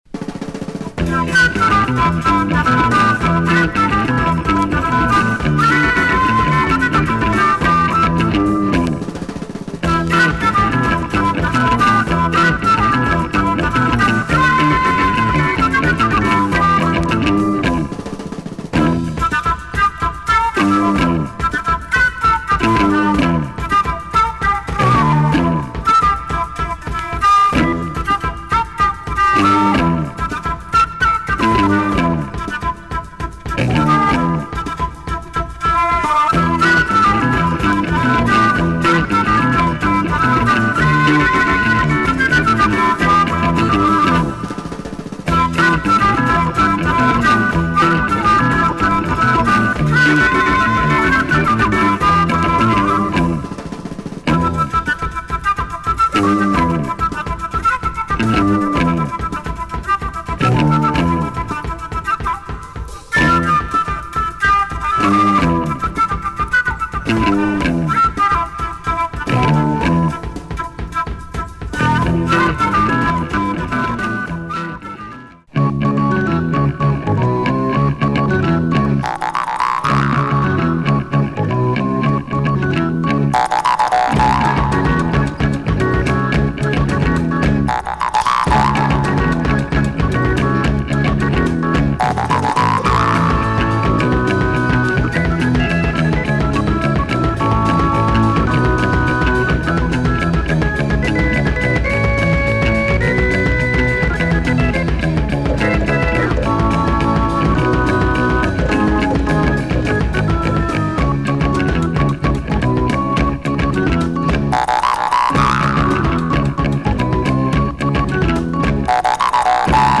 psych pop with organ